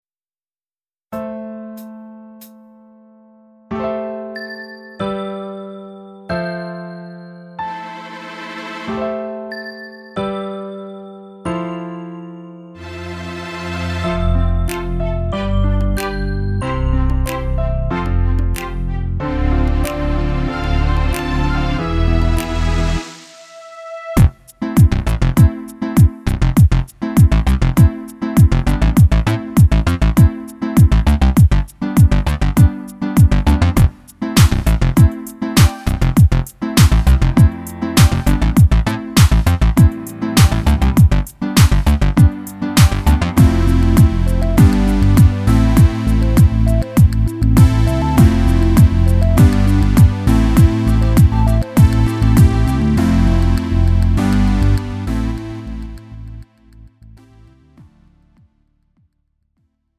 음정 -1키 3:07
장르 가요 구분 Lite MR